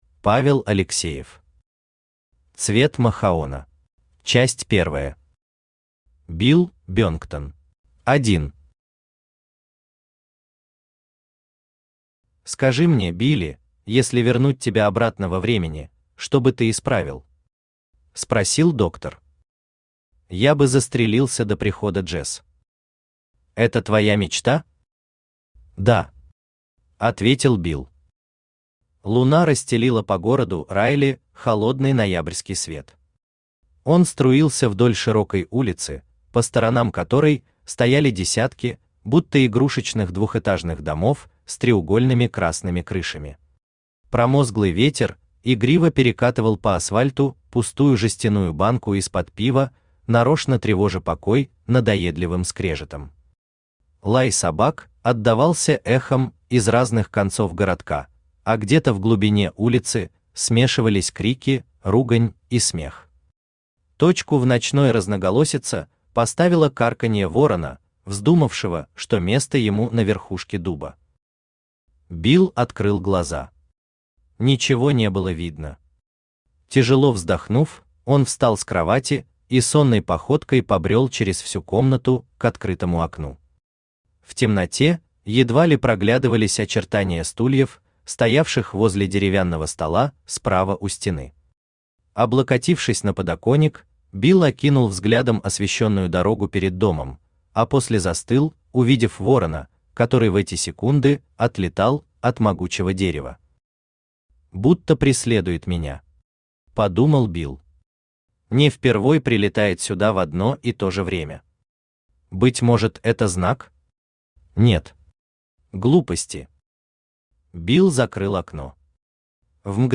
Аудиокнига Цвет махаона | Библиотека аудиокниг
Aудиокнига Цвет махаона Автор Павел Николаевич Алексеев Читает аудиокнигу Авточтец ЛитРес.